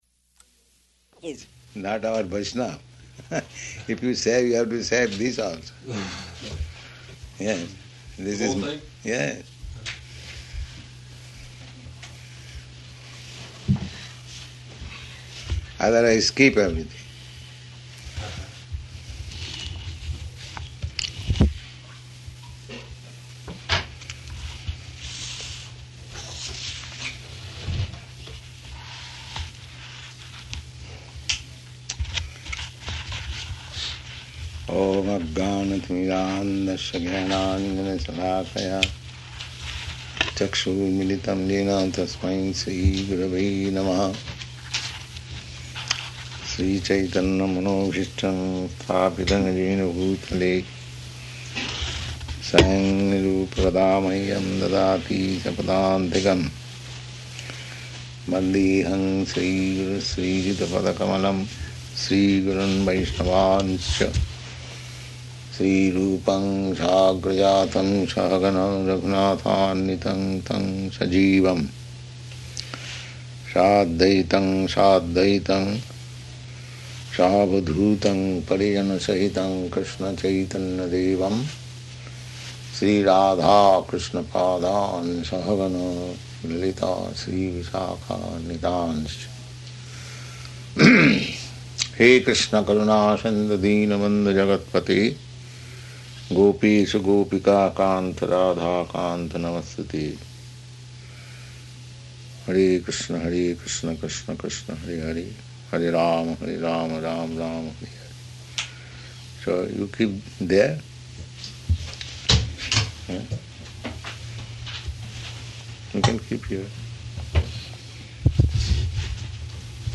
Śrīmad-Bhāgavatam 1.5.15–17 --:-- --:-- Type: Srimad-Bhagavatam Dated: June 19th 1969 Location: New Vrindavan Audio file: 690619SB-NEW_VRINDAVAN.mp3 Prabhupāda: ...is not our Vaiṣṇava.